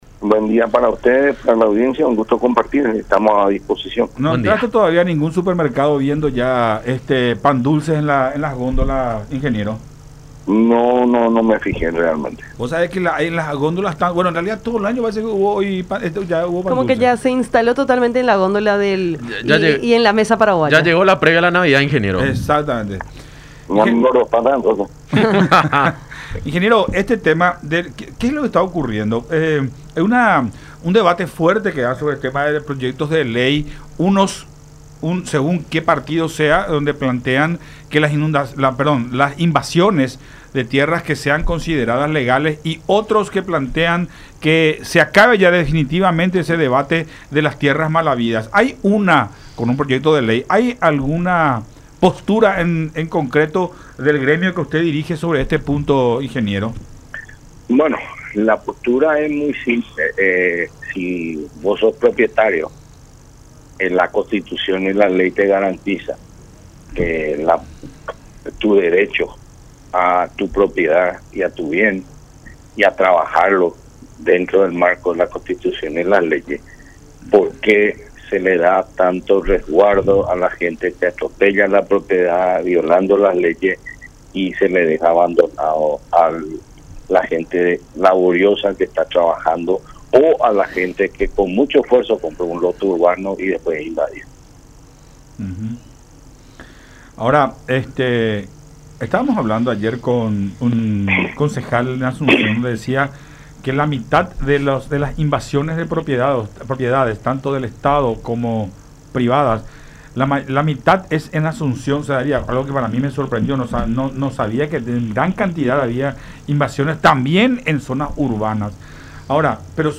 en conversación con Enfoque 800 por La Unión.